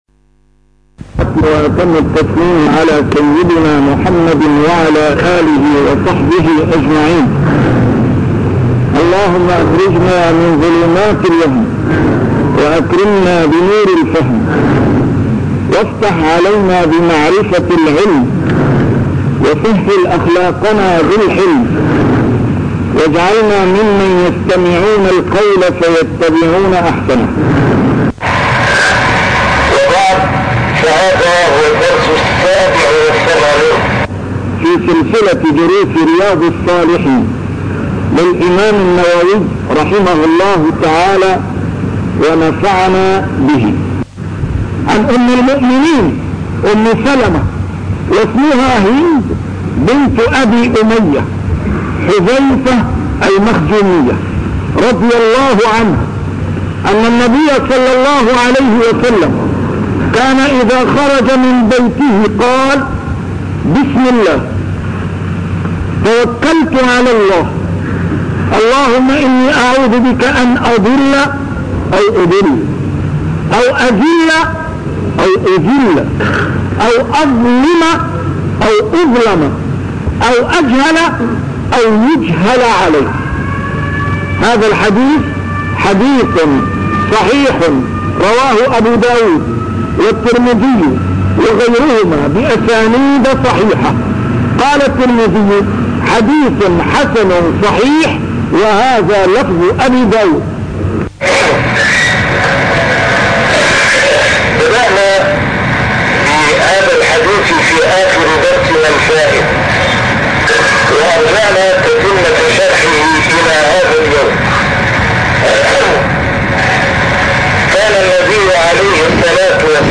A MARTYR SCHOLAR: IMAM MUHAMMAD SAEED RAMADAN AL-BOUTI - الدروس العلمية - شرح كتاب رياض الصالحين - 87- شرح رياض الصالحين: اليقين والتوكل